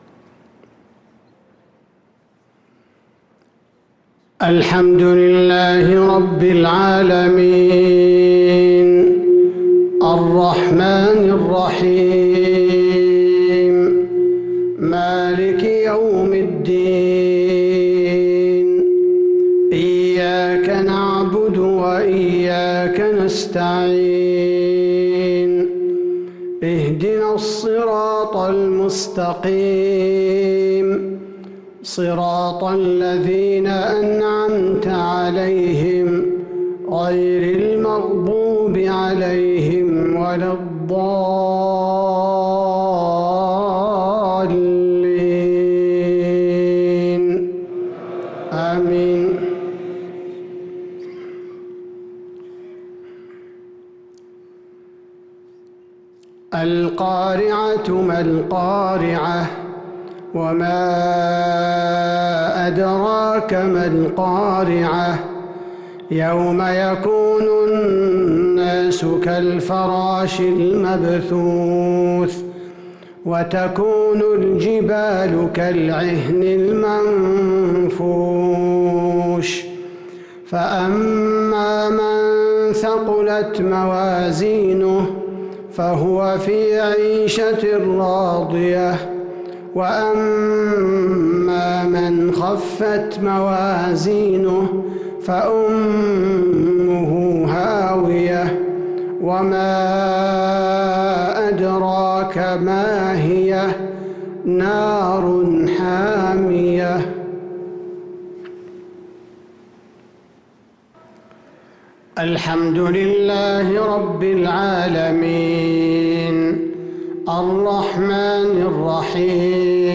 صلاة المغرب للقارئ عبدالباري الثبيتي 10 ذو الحجة 1443 هـ
تِلَاوَات الْحَرَمَيْن .